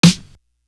Bumpy Snare.wav